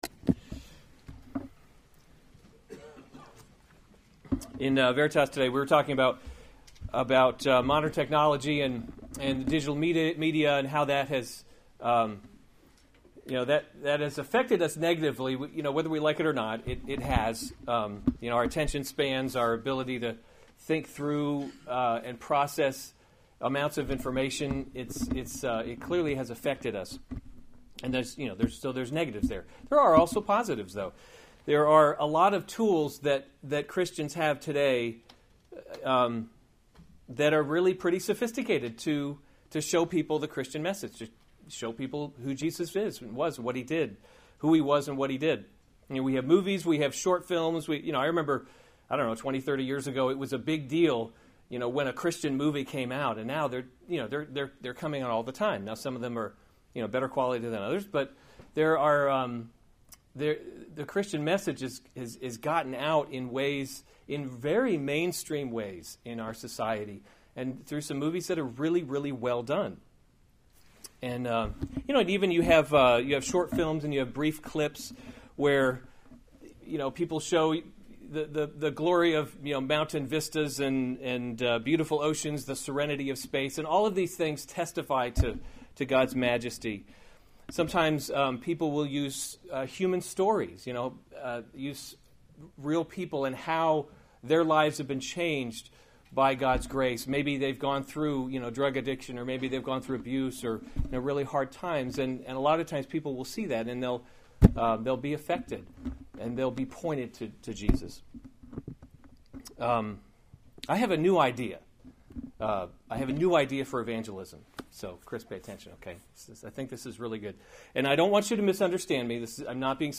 April 8, 2017 1 Timothy – Leading by Example series Weekly Sunday Service Save/Download this sermon 1 Timothy 4:1-5 Other sermons from 1 Timothy Some Will Depart from the Faith […]